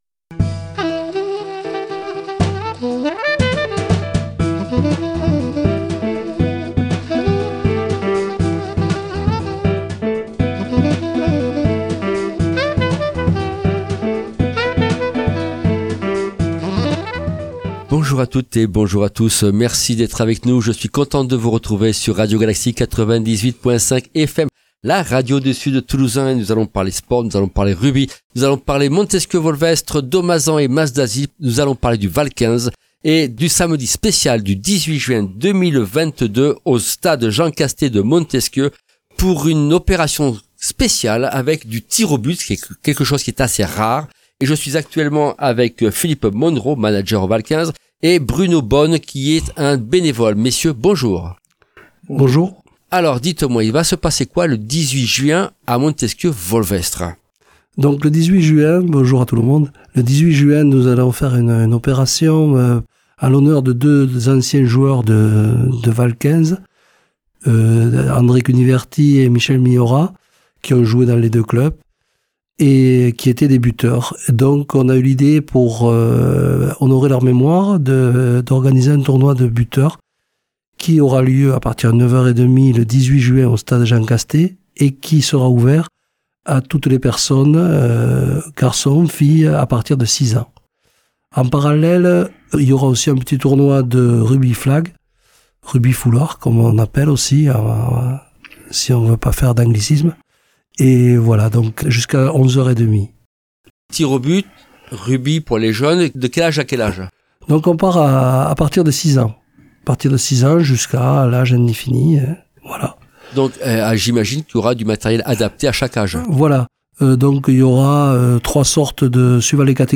Genre : Inteview.